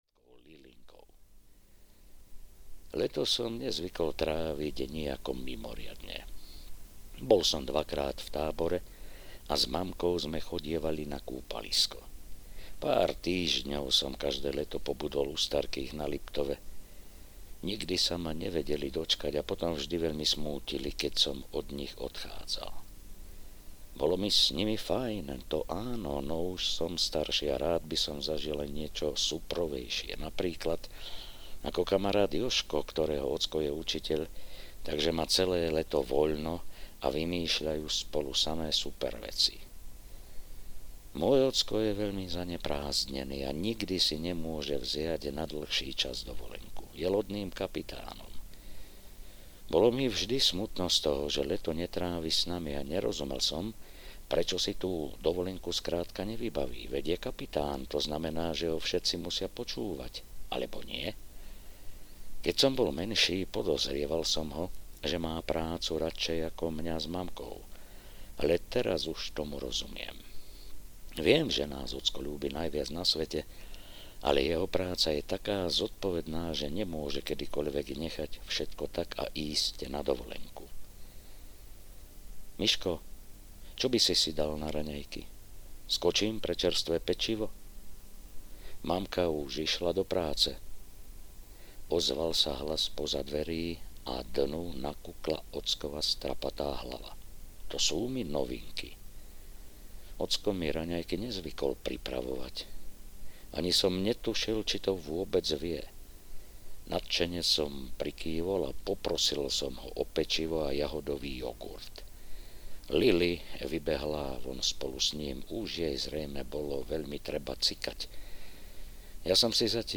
Vodné dobrodružstvá audiokniha
Ukázka z knihy